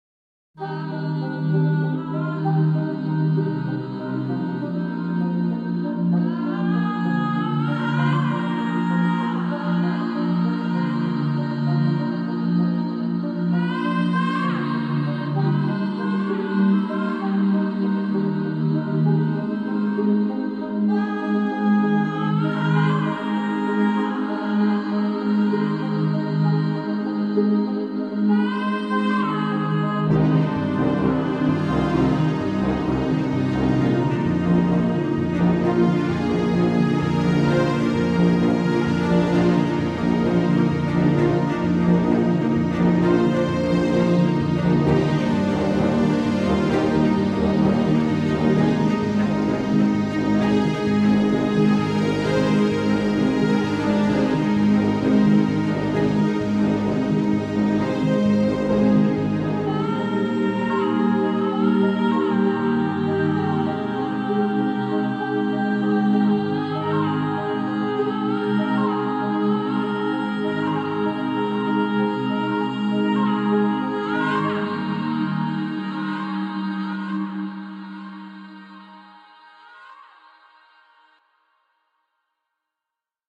ここではFantasywaveを選びました。